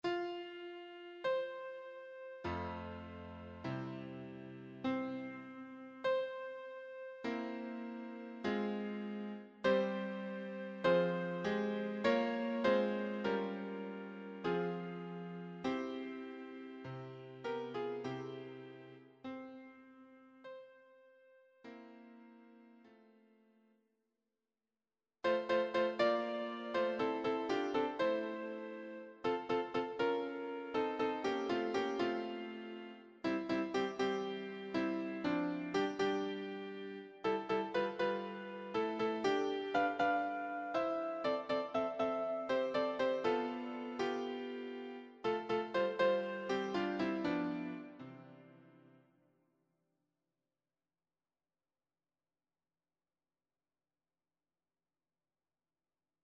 choir SATB